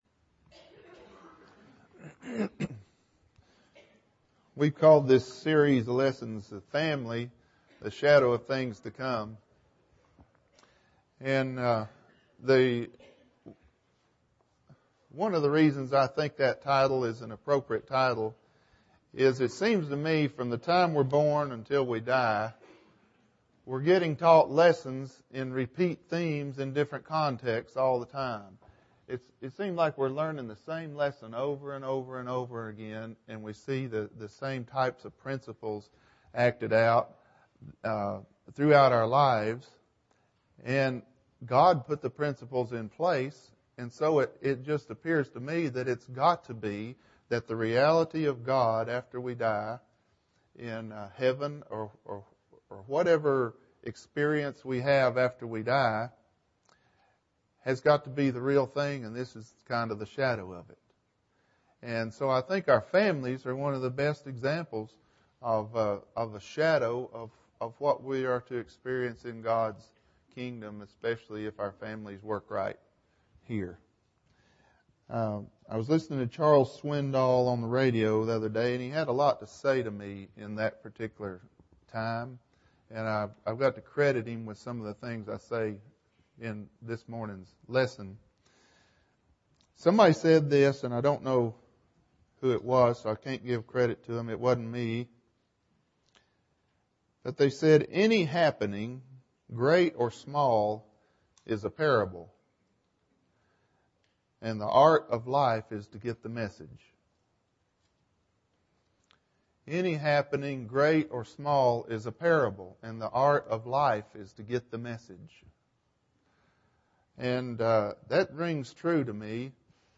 Family and Principles (10 of 12) – Bible Lesson Recording